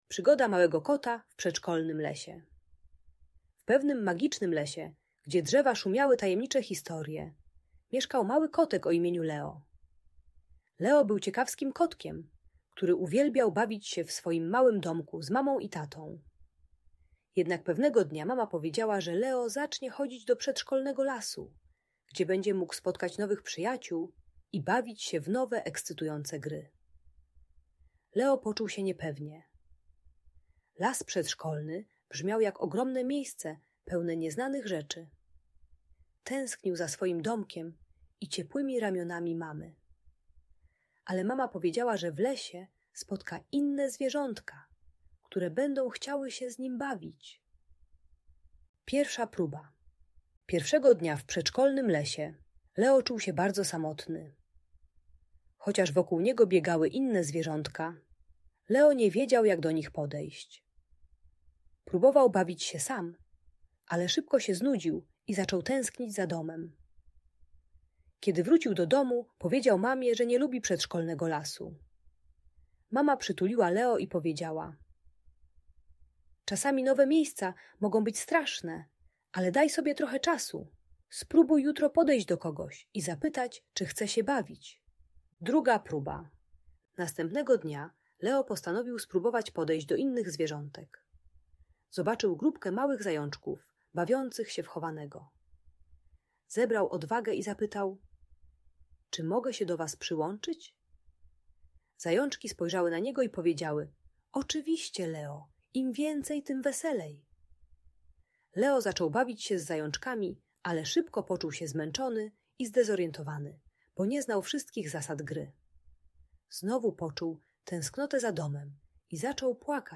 Bajka o adaptacji w przedszkolu dla dzieci 3-4 lata, które płaczą przy rozstaniu z rodzicami. Ta audiobajka o lęku separacyjnym pomaga maluchowi zrozumieć, że przedszkole może być fajnym miejscem pełnym przyjaciół. Uczy techniki małych kroków - stopniowego oswajania się z nowym miejscem i angażowania się w zabawę z innymi dziećmi.